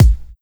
pcp_kick05.wav